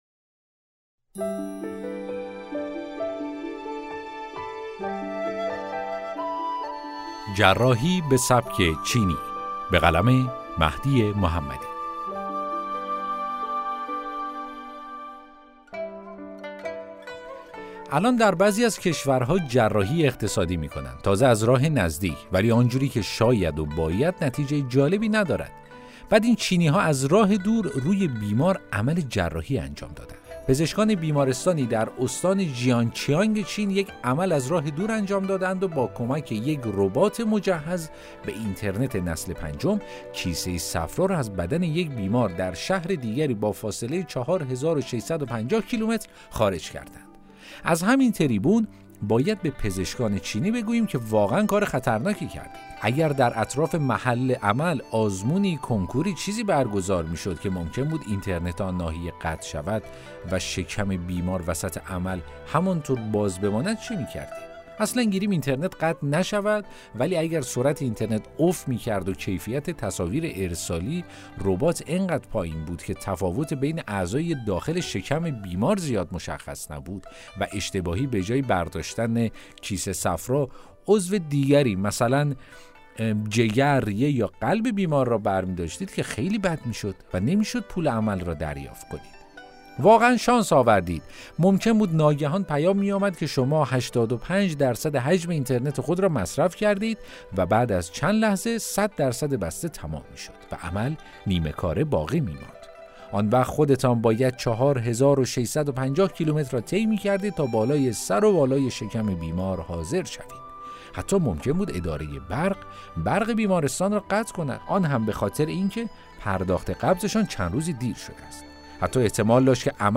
داستان صوتی: جراحی به سبک چینی